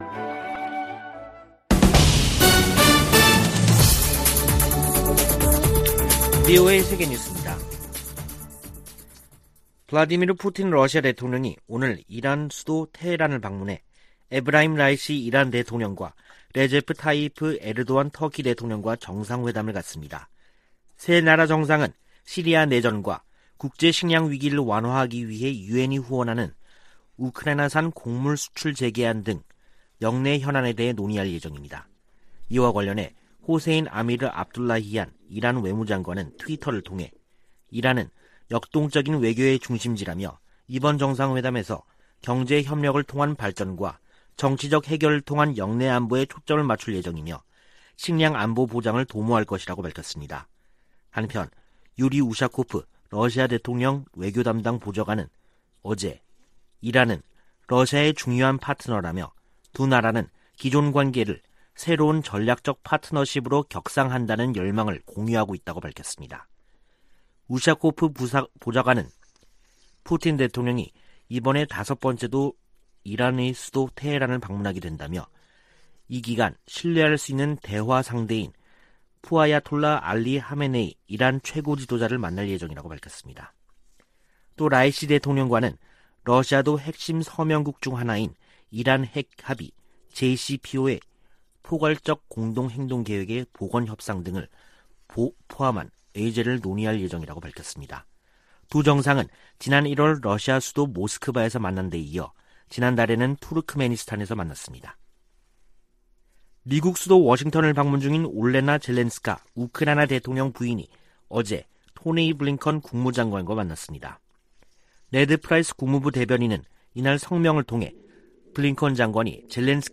VOA 한국어 간판 뉴스 프로그램 '뉴스 투데이', 2022년 7월 19일 3부 방송입니다. 주한미군은 미한 공동 안보 이익을 방어하기 위해 필요하다는 입장을 미 국방부가 확인했습니다. 한국을 방문한 미 재무장관은 탄력성 있는 공급망 구축을 위한 협력을 강조하며 중국의 시장 지배적 지위를 막아야 한다고 말했습니다. 미 국무부가 북한의 인권 상황은 대량살상무기 만큼이나 우려스러운 부분이라고 지적했습니다.